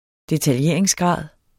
Udtale [ detalˈjeˀɐ̯eŋs- ]